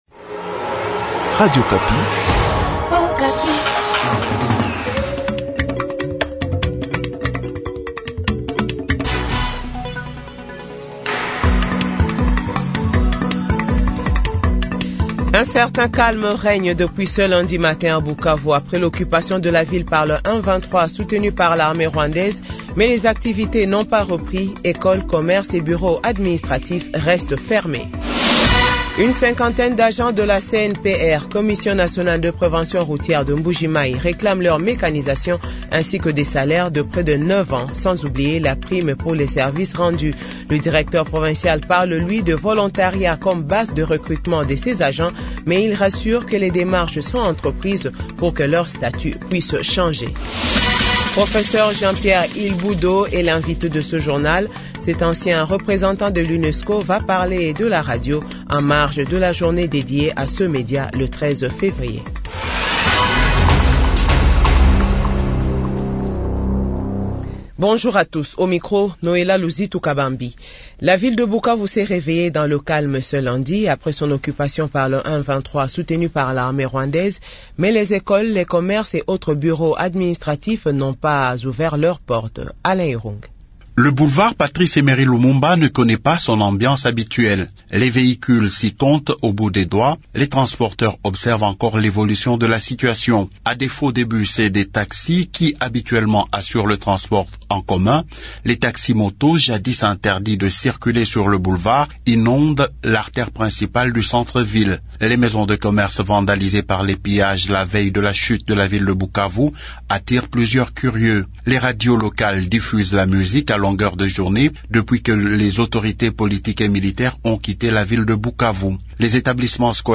Journal 12h00